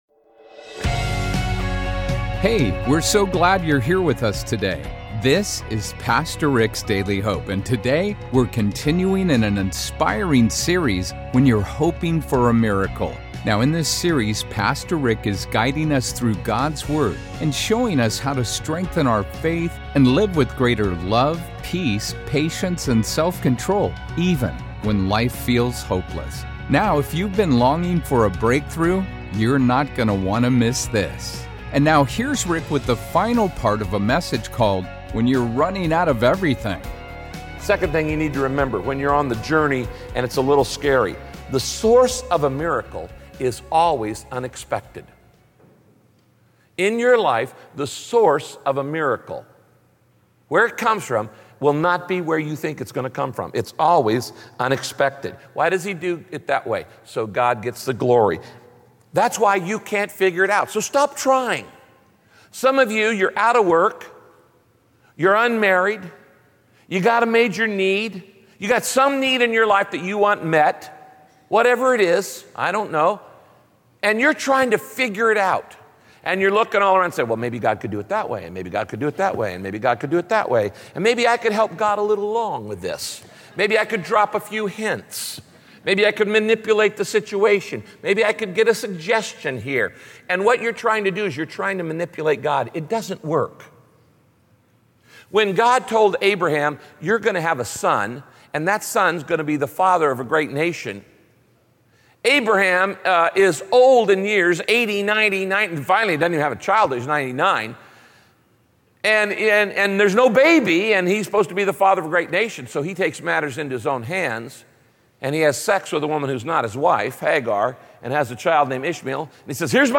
Join Pastor Rick as he teaches how God’s miracles always come in unexpected ways and what you need to remember as you wait for your miracle.